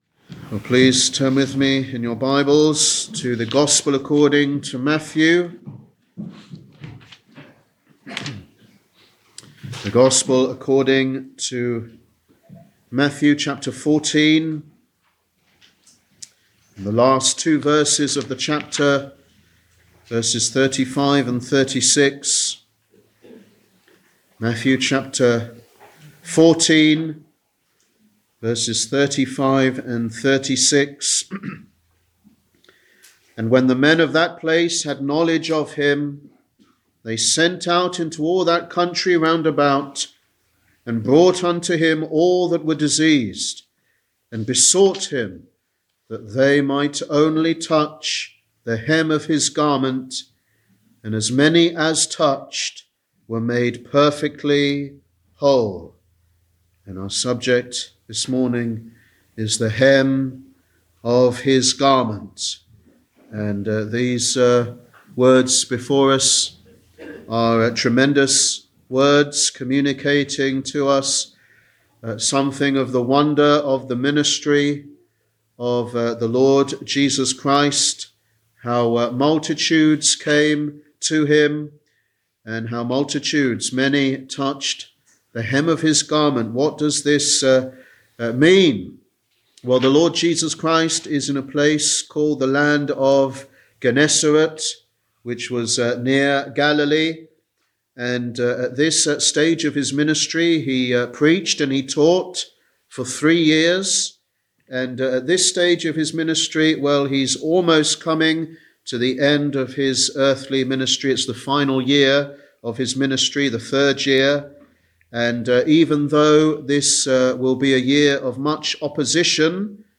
Sunday Evangelistic Service